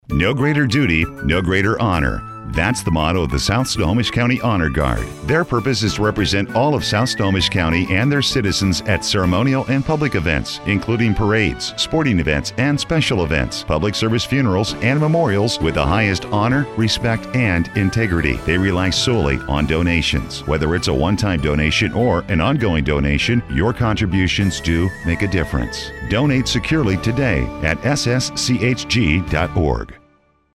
On The Radio
Listen to the PSA being played on your favorite radio stations.